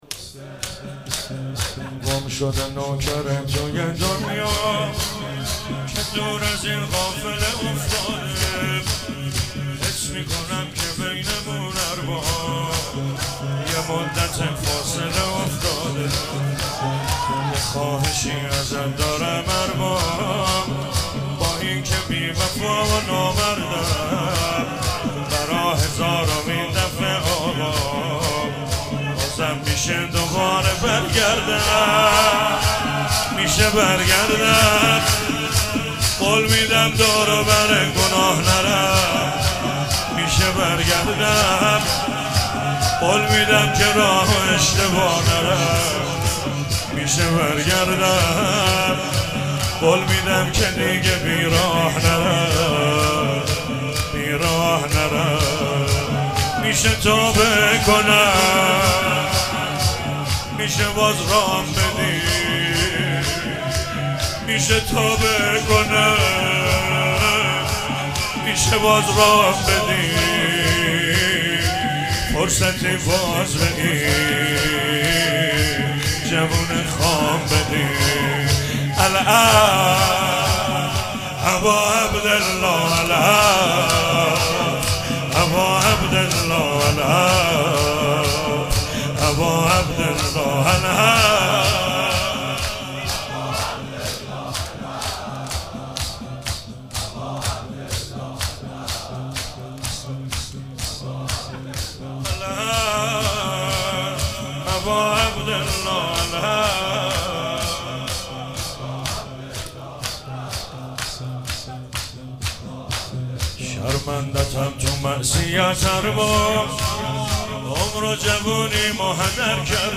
چهاراه شهید شیرودی حسینیه حضرت زینب (سلام الله علیها)
شور- الله اکبر به یل خیبر